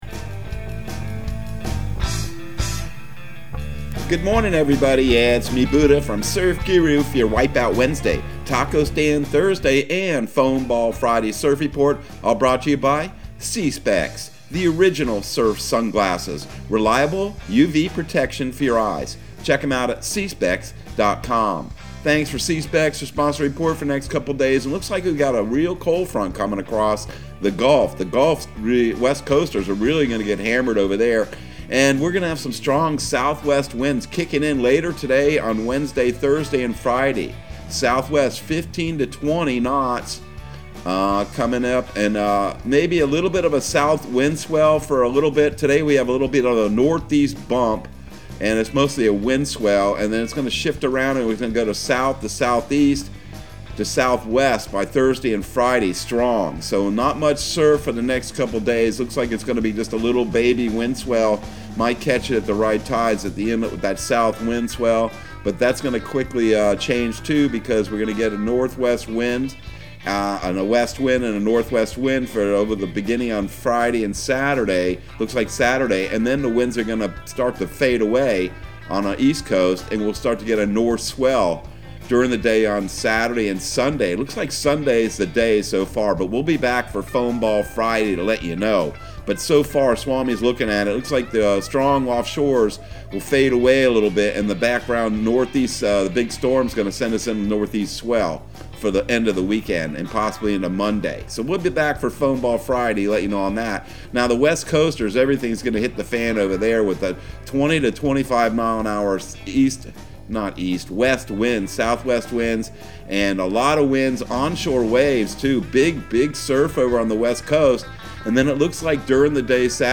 Surf Guru Surf Report and Forecast 10/27/2021 Audio surf report and surf forecast on October 27 for Central Florida and the Southeast.